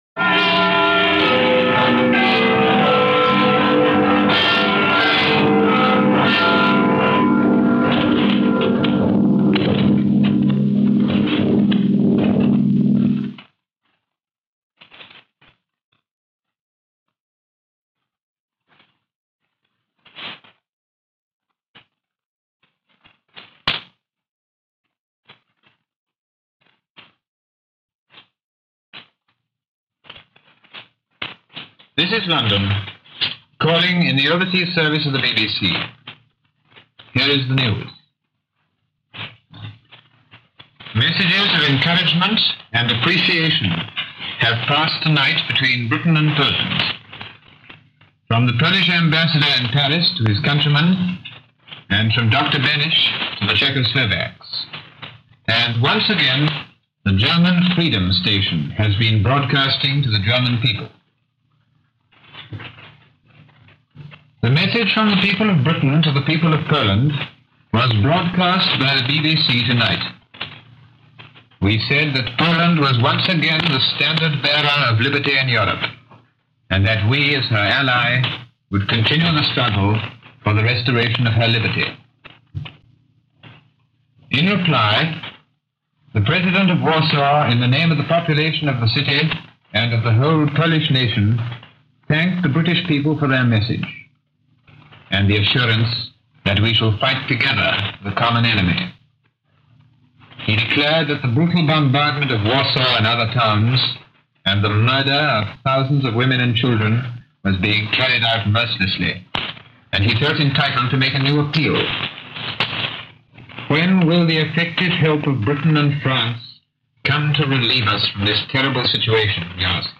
War In Europe: Day 17 - September 19, 1939 - news from the BBC Overseas Service for September 19, 1939.